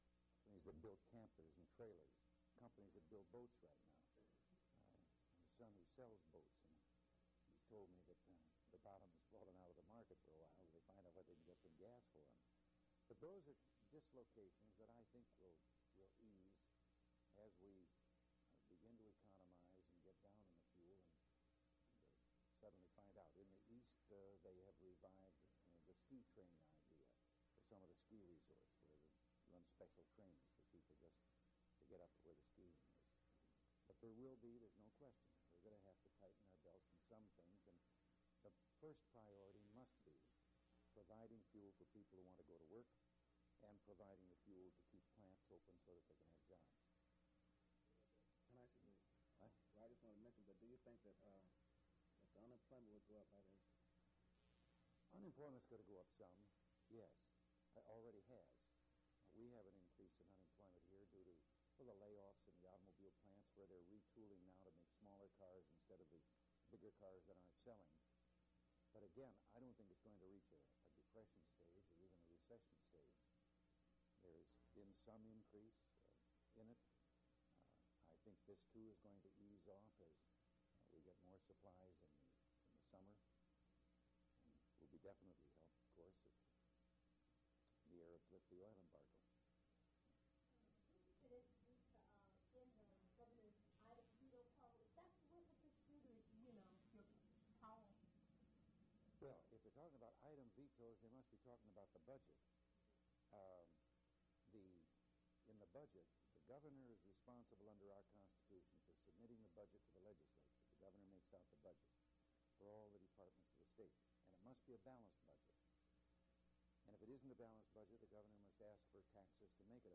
Governor Ronald Reagan Question and Answer with students
Audio Cassette Format (in progress).